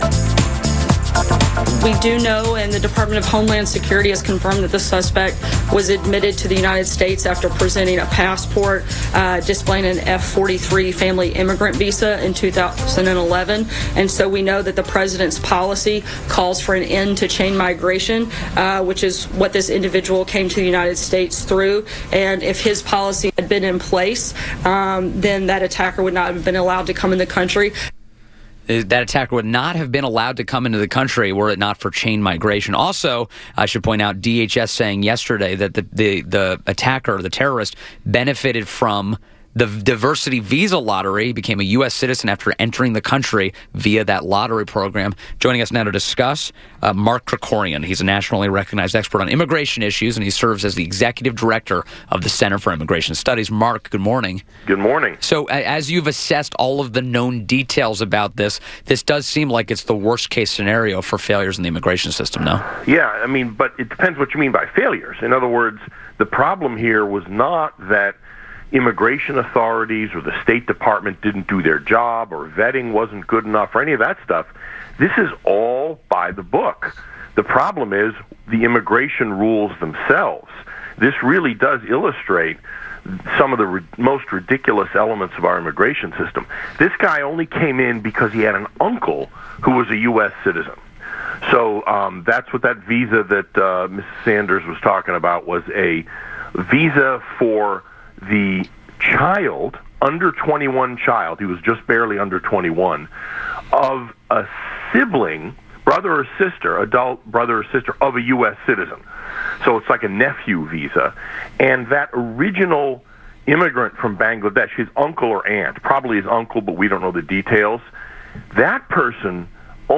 WMAL Interview